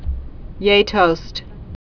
(yātōst, yĕ-, jā-)